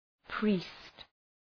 Προφορά
{pri:st}
priest.mp3